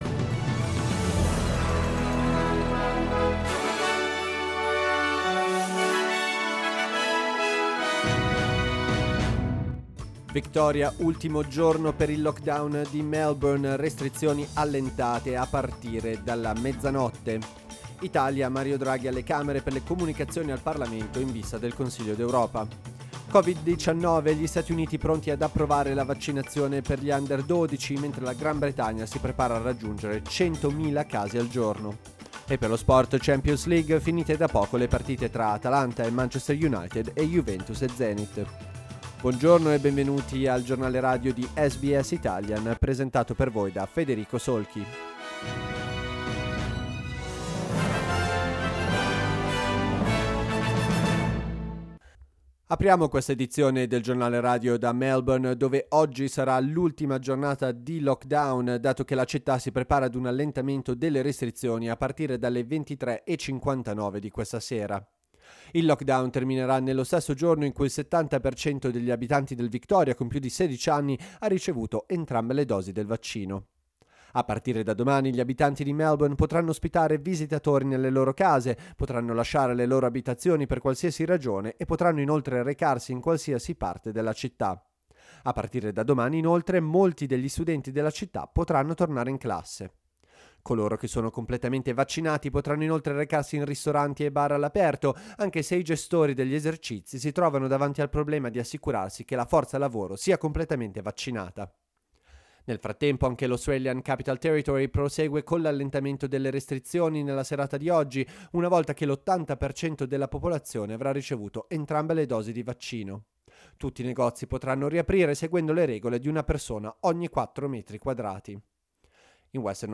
Giornale radio giovedì 21 ottobre 2021
Il notiziario di SBS in italiano.